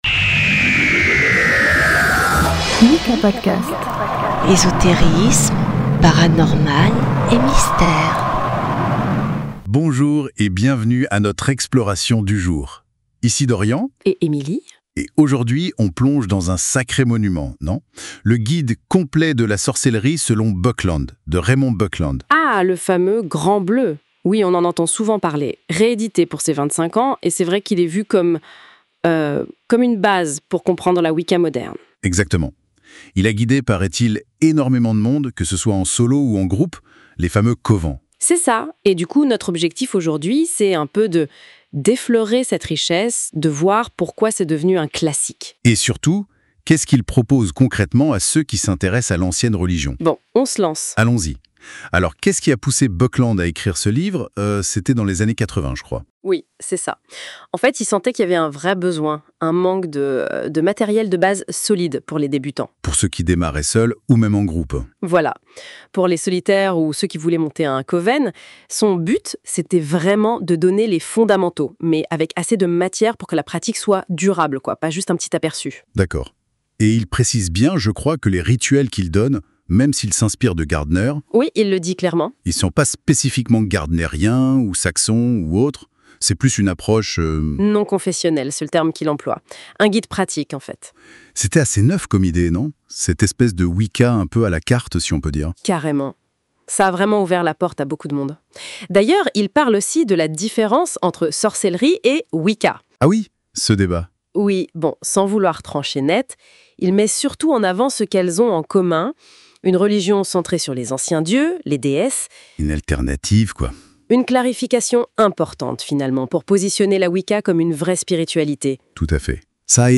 🕯 Entre deux blagues de sorcier·e et un soupçon d’humour espiègle (on n’est pas des robots sérieux, promis juré 💀✨), on vous donne envie de vous plonger dedans à votre tour.
✅ Bénéficier d’une présentation claire, documentée et amusante.